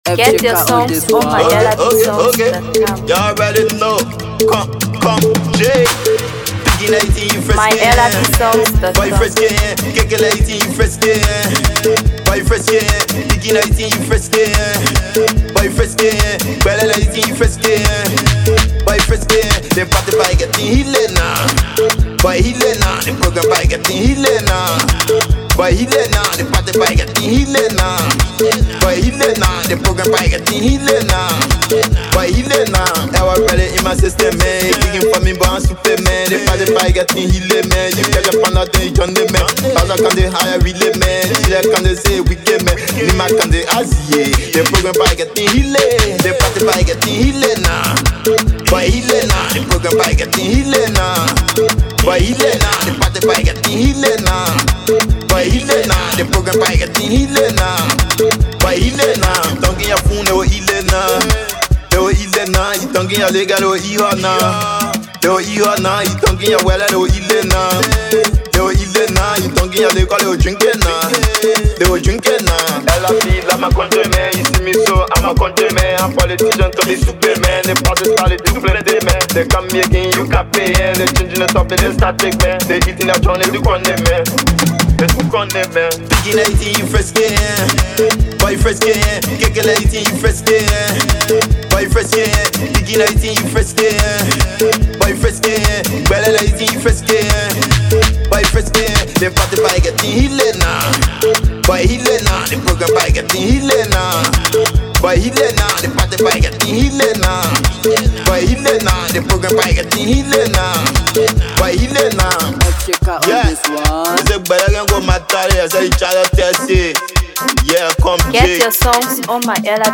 Afro PopMusic